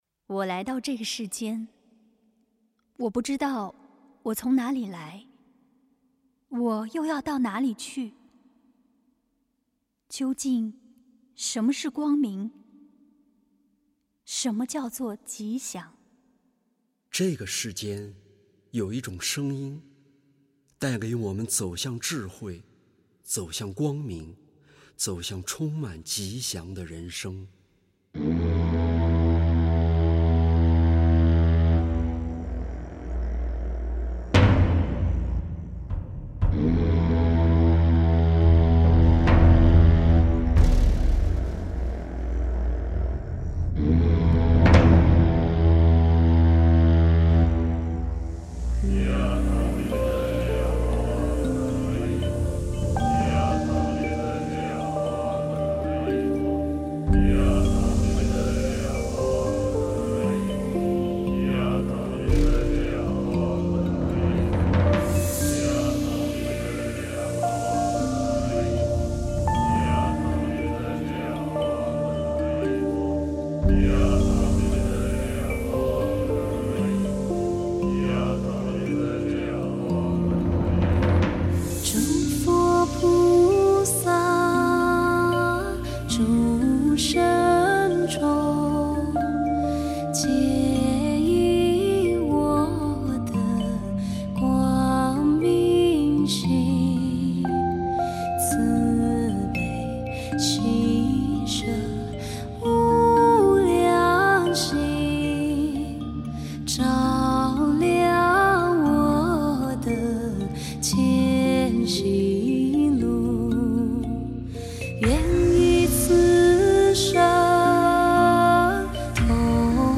旁白（女）